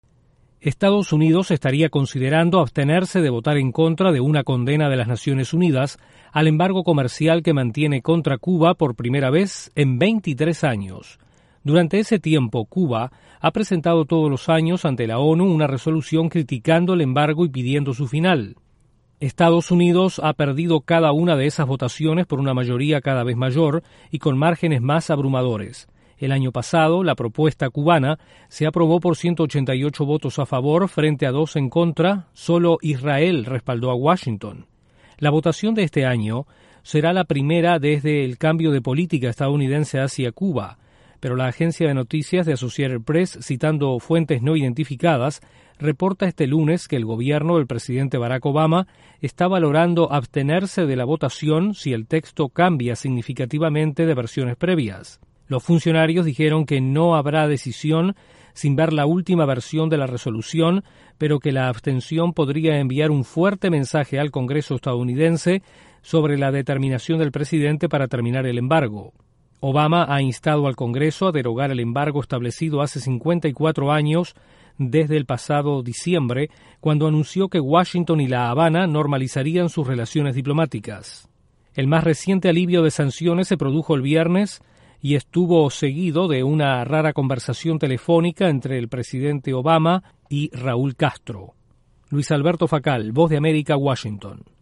Estados Unidos considera abstenerse de votar en contra del embargo a Cuba en las Naciones Unidas. Desde la Voz de América en Washington informa